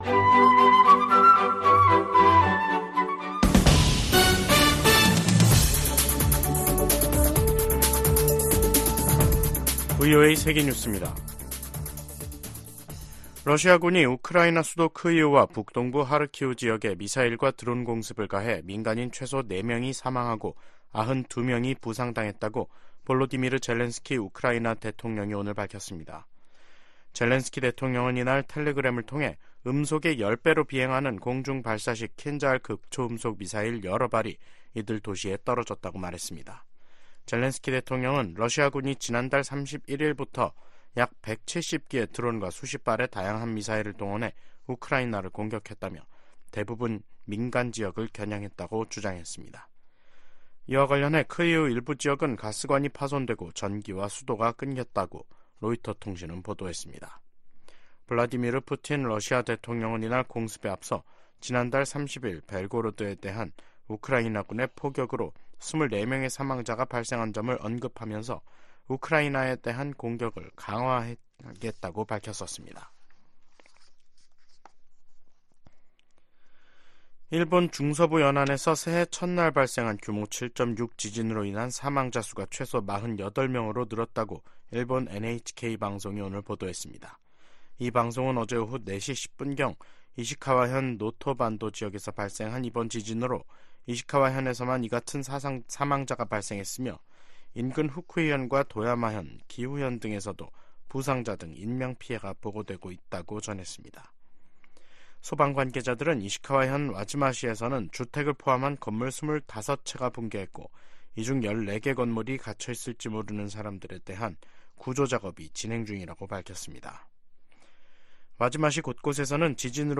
VOA 한국어 간판 뉴스 프로그램 '뉴스 투데이', 2024년 1월 2일 2부 방송입니다. 미 국무부가 김정은 북한 국무위원장의 추가 위성 발사 예고에 대륙간탄도미사일(ICBM) 개발과 다름없는 것이라는 입장을 밝혔습니다. 한국이 2년간의 유엔 안전보장이사회 비상임이사국 활동을 시작했습니다. 북한과 러시아가 미국의 금융 제재망을 우회해 무기 거래를 지속할 우려가 있다고 미국 전문가들이 지적했습니다.